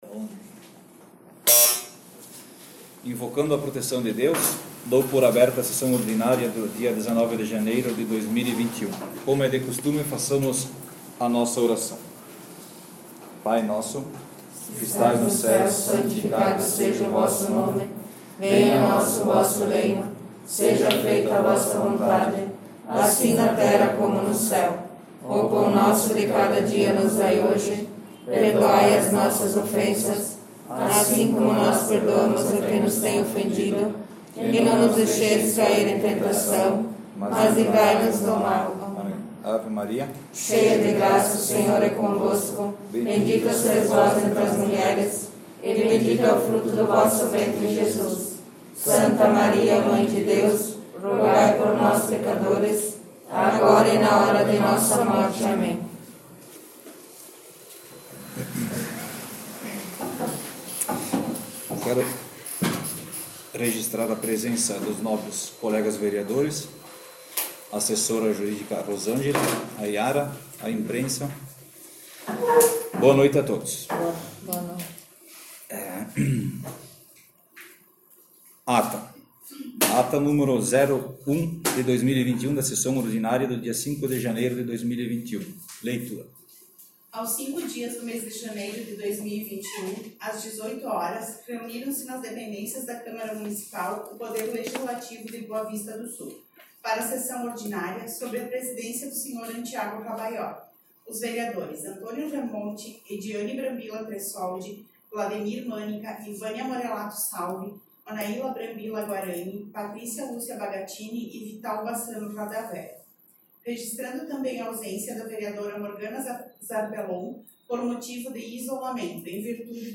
Sessão Ordinária 19/01/2021 — Câmara Municipal de Boa Vista do Sul
Sessão Ordinária 19/01/2021